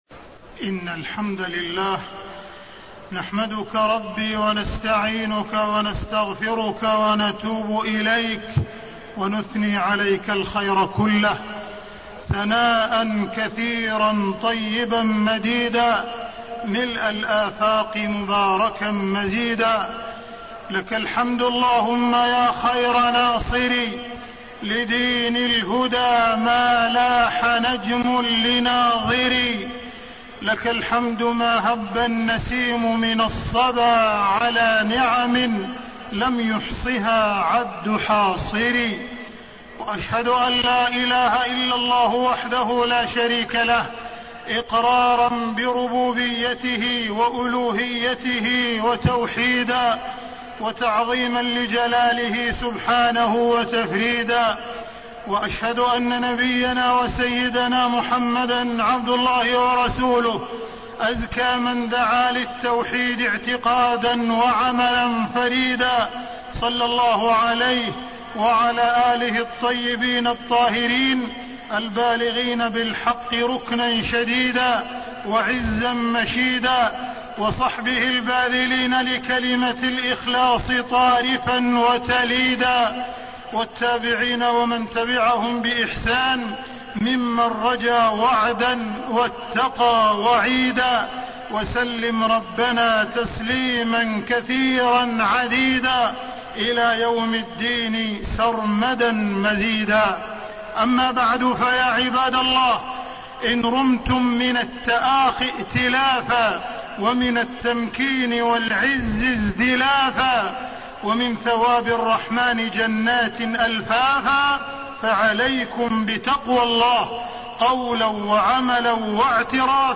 تاريخ النشر ٢١ محرم ١٤٣٣ هـ المكان: المسجد الحرام الشيخ: معالي الشيخ أ.د. عبدالرحمن بن عبدالعزيز السديس معالي الشيخ أ.د. عبدالرحمن بن عبدالعزيز السديس فضل كلمة التوحيد لا إله إلا الله